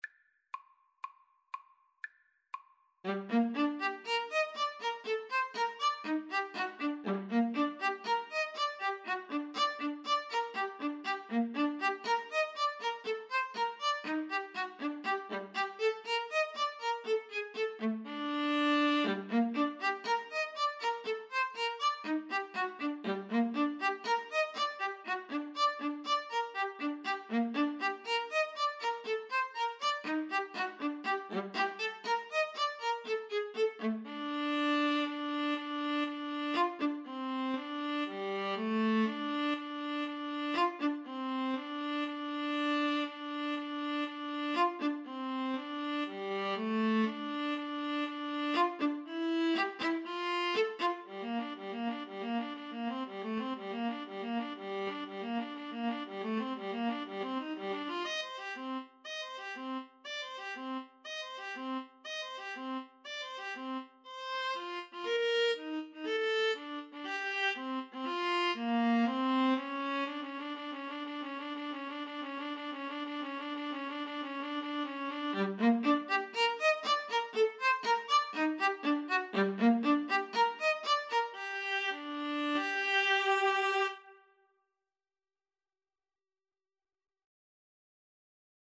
Free Sheet music for Clarinet-Viola Duet
G minor (Sounding Pitch) (View more G minor Music for Clarinet-Viola Duet )
Allegro (View more music marked Allegro)
Classical (View more Classical Clarinet-Viola Duet Music)